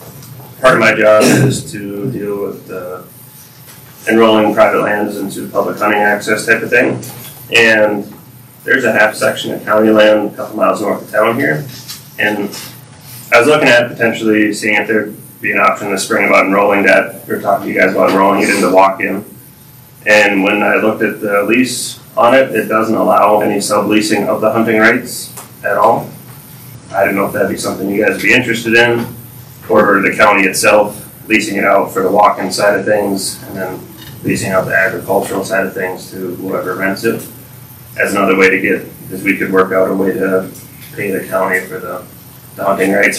The Walworth County Commission held a regular meeting on Wednesday, Dec. 10th.